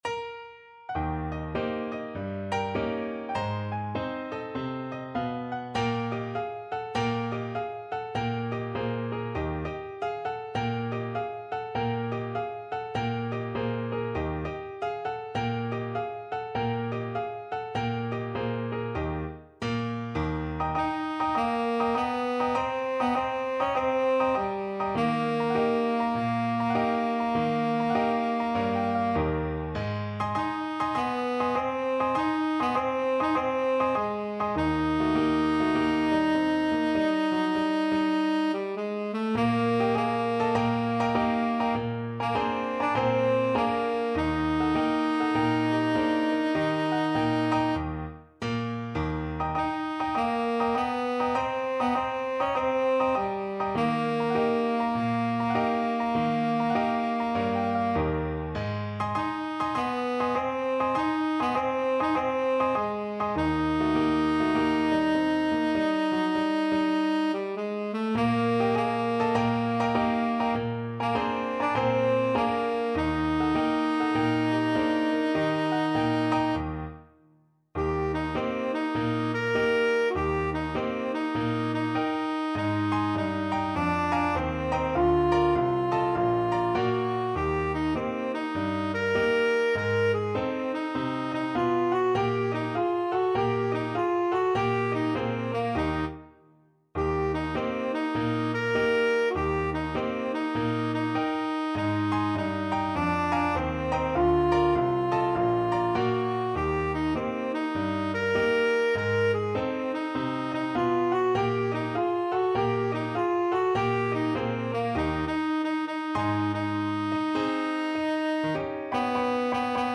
4/4 (View more 4/4 Music)
Jazz (View more Jazz Tenor Saxophone Music)